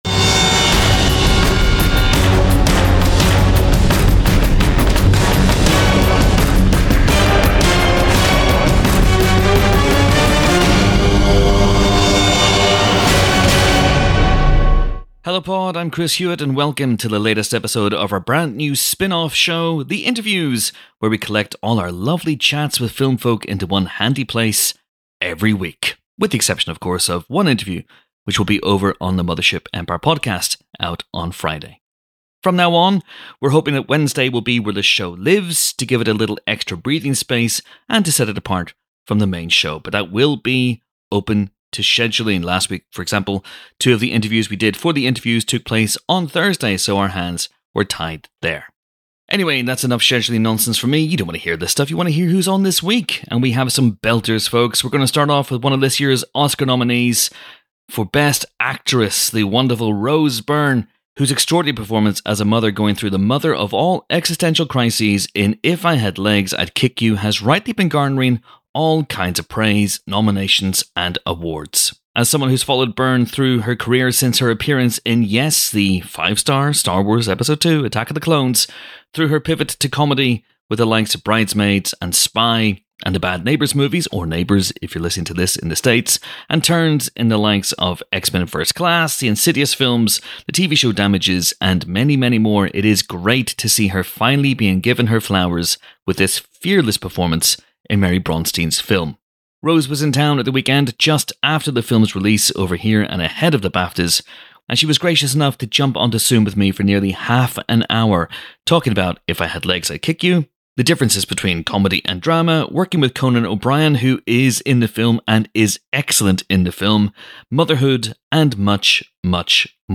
The Interviews (ft. Rose Byrne, Neve Campbell, Corin Hardy)